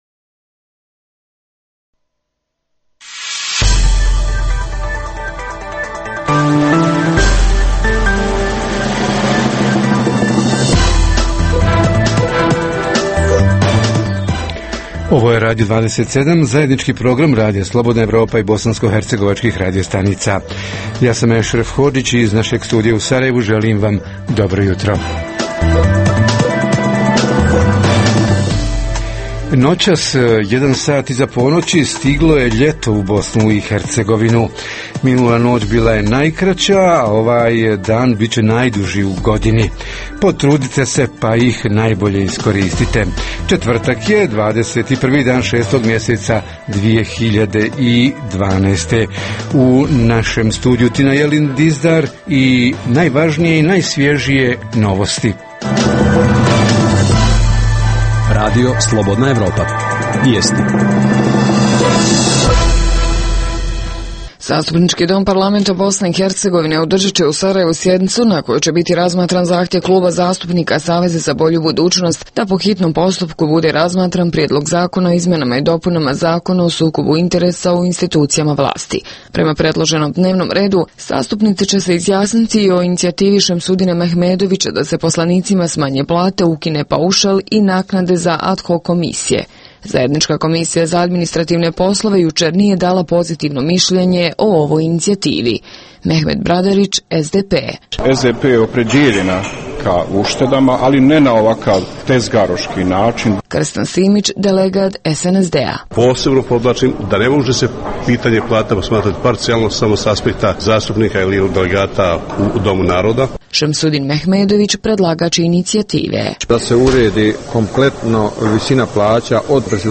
Tema jutra: Uoči predstojećih lokalnih izbora u BiH – kako da u lokalnoj vlasti bude više žena? Reporteri iz cijele BiH javljaju o najaktuelnijim događajima u njihovim sredinama.
Redovni sadržaji jutarnjeg programa za BiH su i vijesti i muzika.